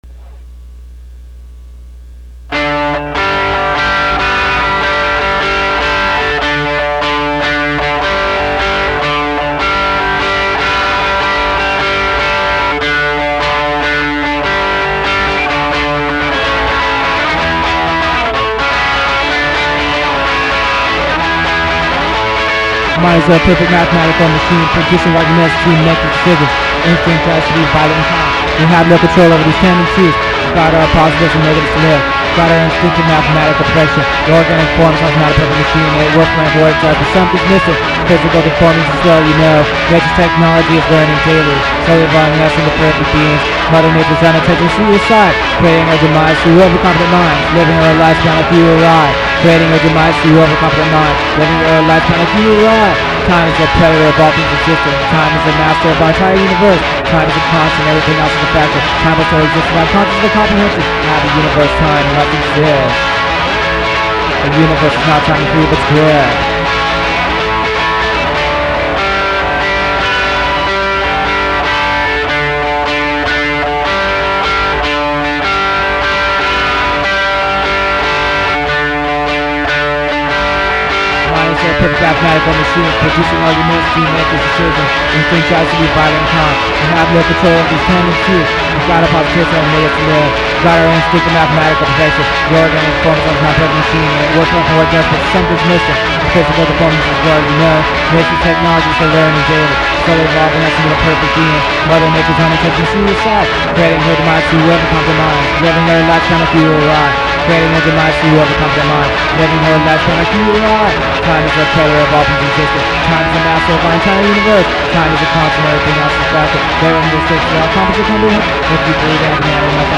I converted some of the four track recordings to digital, with much difficulty.